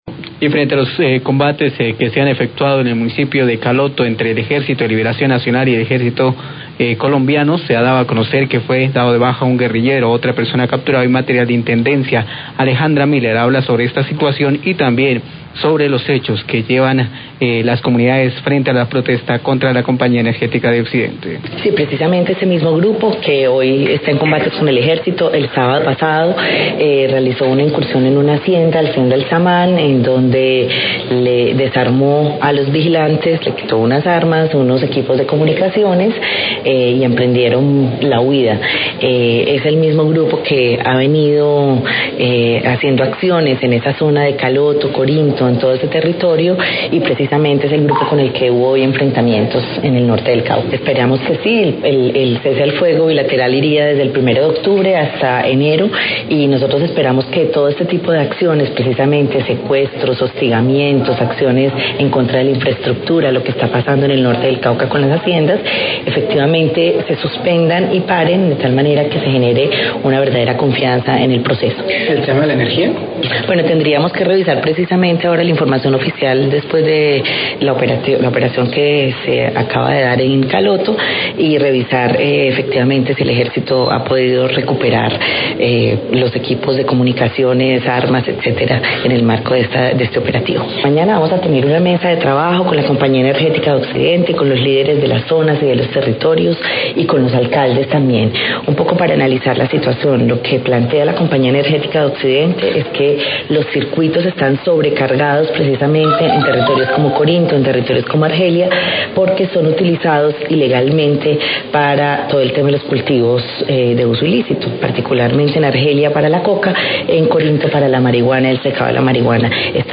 Radio
Alejandra Miller, Secretaria de Gobierno del Cauca, habla de la muerte de un guerrillero en Caloto en enfrentamientos con el Ejército Nacional. También se refiere a los hechos que llevan a protestas en Corinto y Argelia contra el servicio de energía de la Compañía Energética.